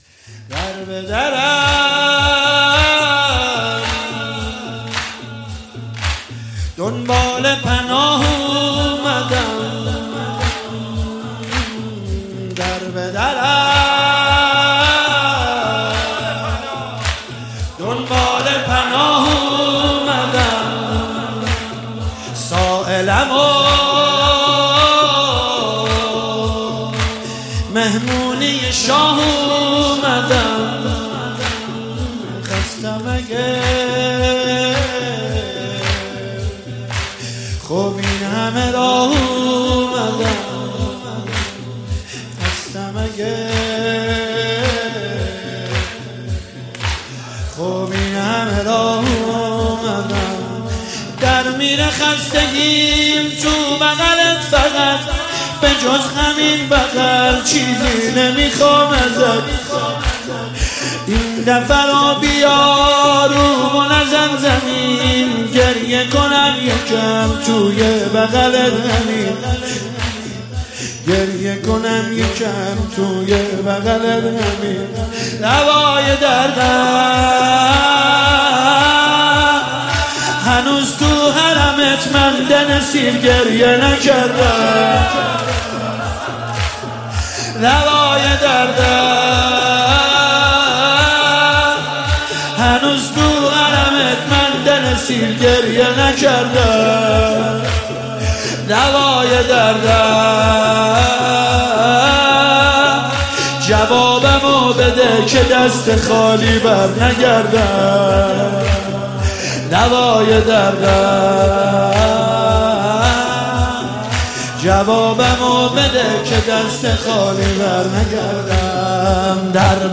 مداحی زیبا و دلنشین